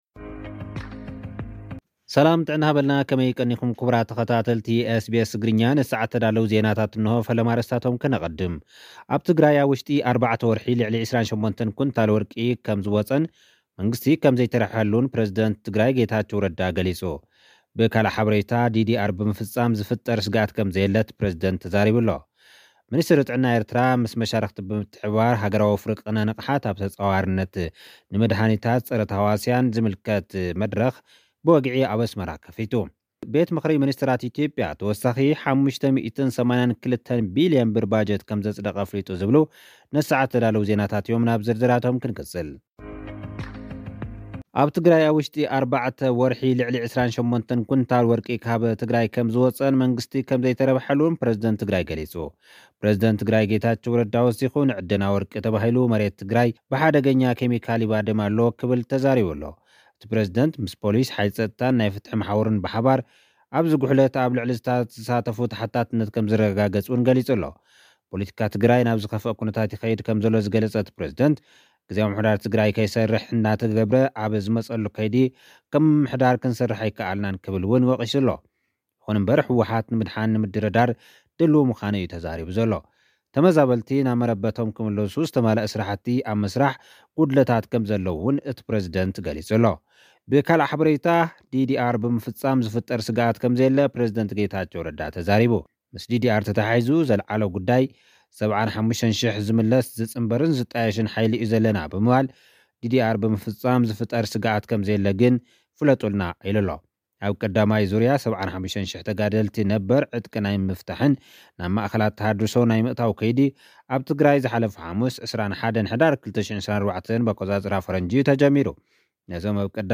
ኣብ ትግራይ ኣብ 4 ወርሒ ልዕሊ 28 ኩንታል ወርቂ ከምዝወፀን መንግስቲ ከም ዘይተረብሐሉን ፕረዚደንት ጌታቸው ረዳ ገሊጹ። (ጸብጻብ)